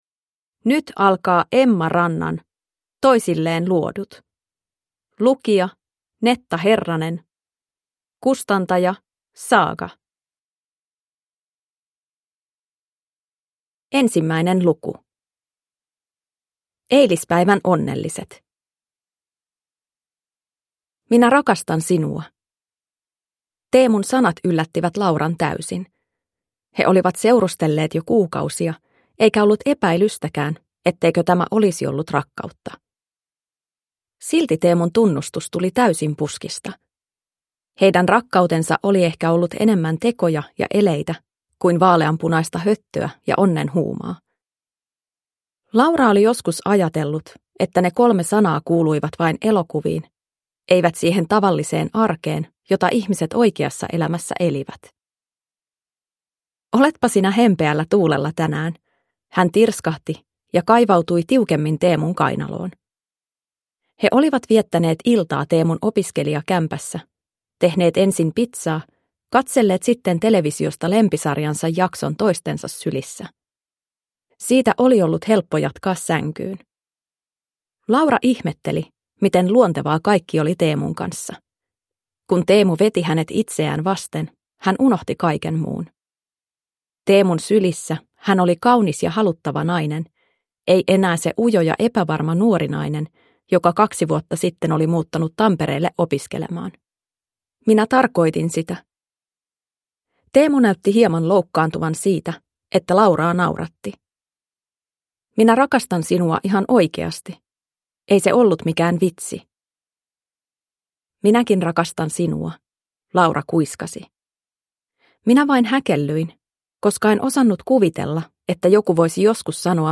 Toisilleen luodut / Ljudbok